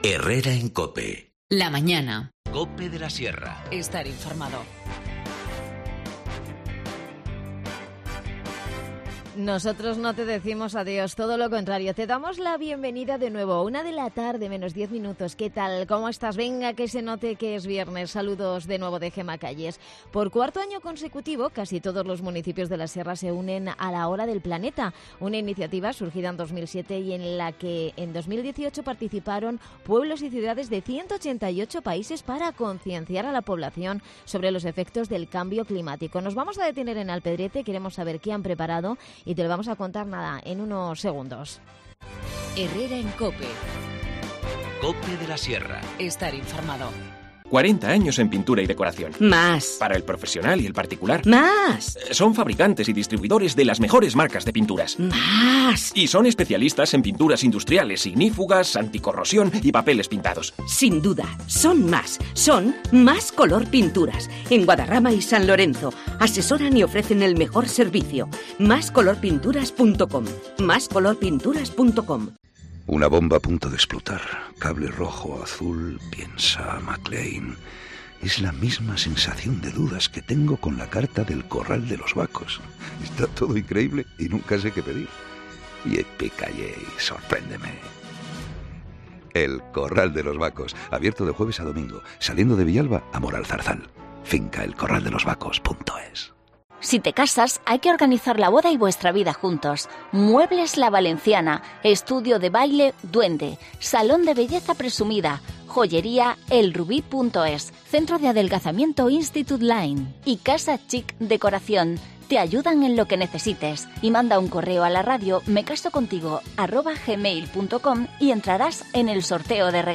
Nos lo cuenta Guiomar Romero, concejal de Medio Ambiente y Energía.